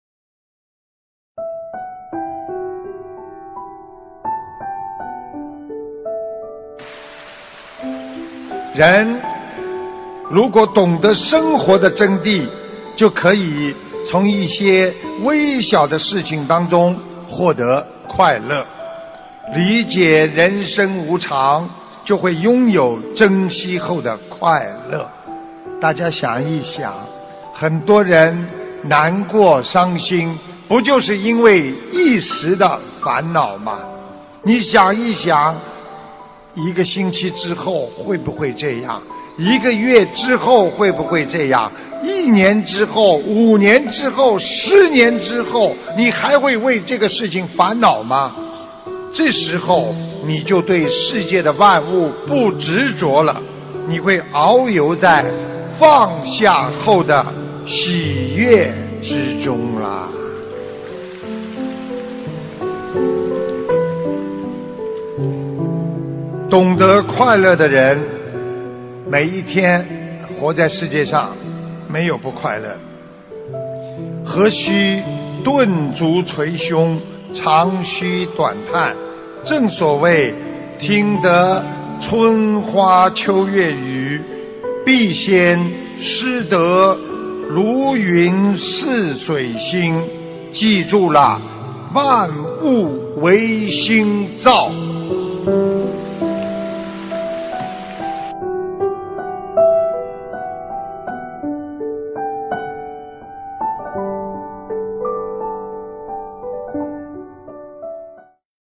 音频：人生真谛！马来西亚吉隆坡！